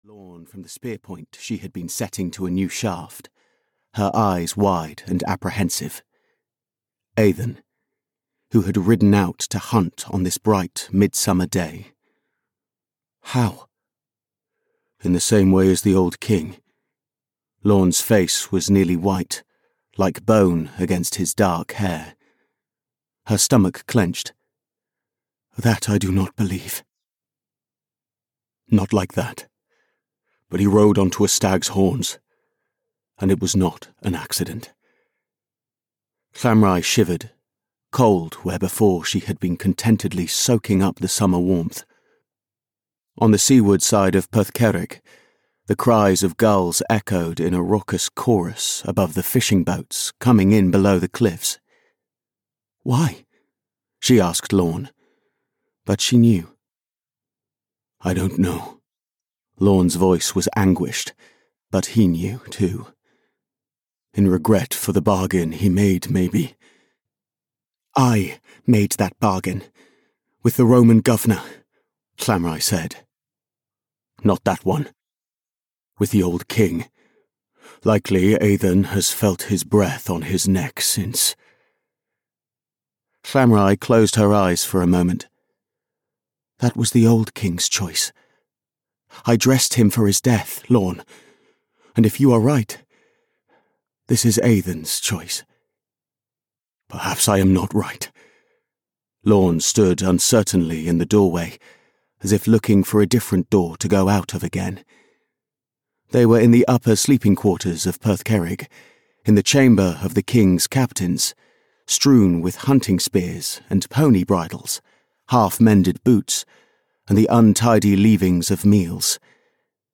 Birds of Prey (EN) audiokniha
Ukázka z knihy